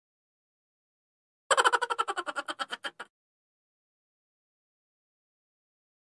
Funny laugh sound effect for your vlog